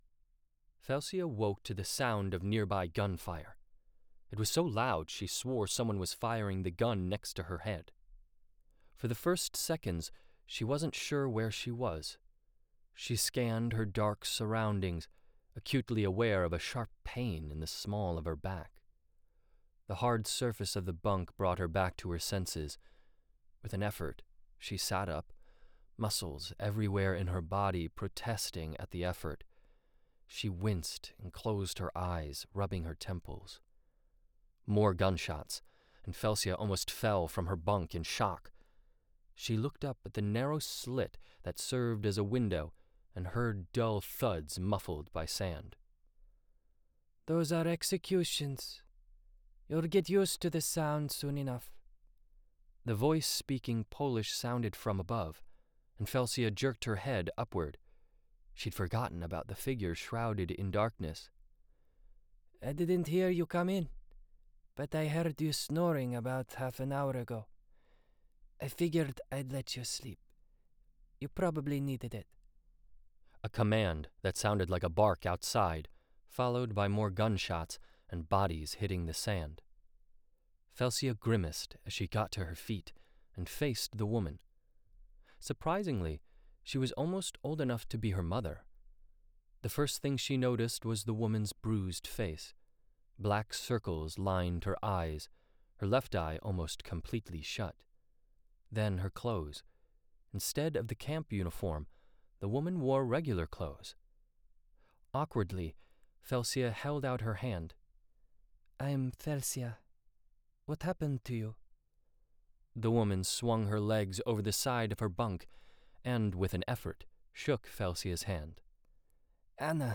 The Botanist's Tracks audiobook - Michael Reit - Buy direct from author!